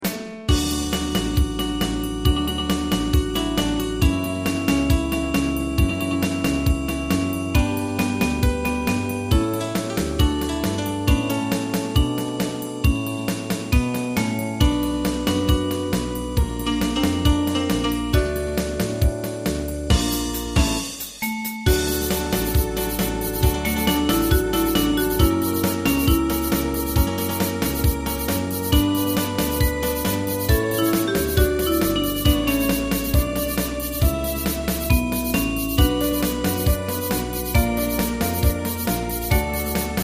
Ensemble musical score and practice for data.